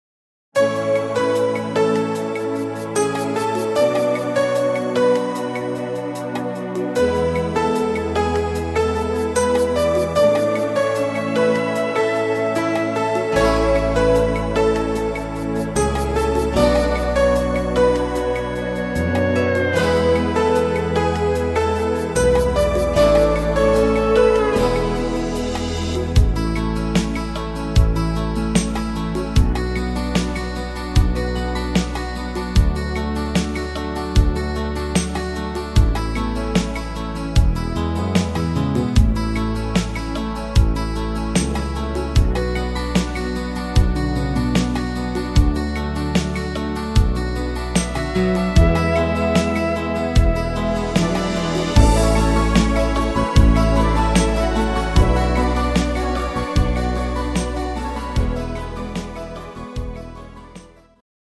Rhythmus  Ballade
Art  Pop, Deutsch